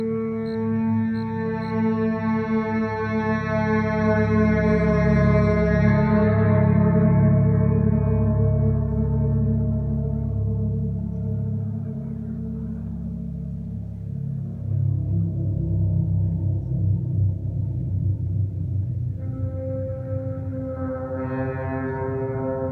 When struck, it rings a loud, rolling bass tone for three minutes.
As it settled on its pendular cable, the bell hummed on and on as the Tank took up its resonance and sang it back in a hundred voices and overtones.